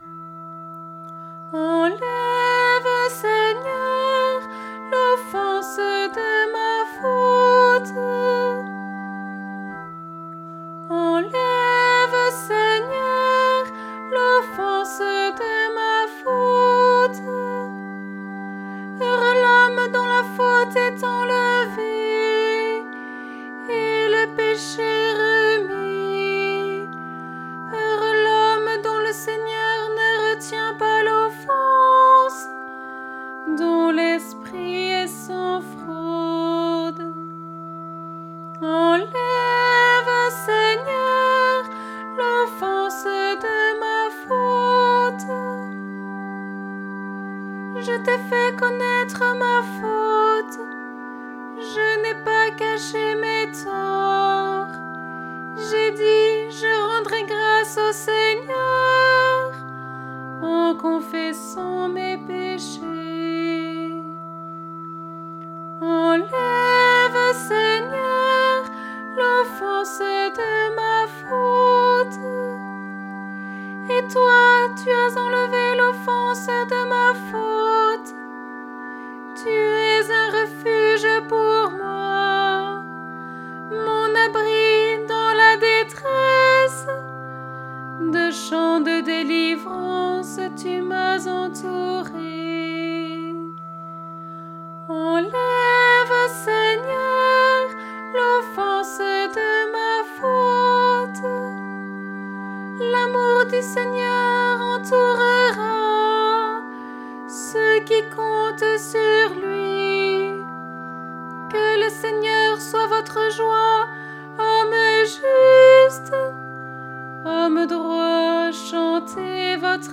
voix de soprane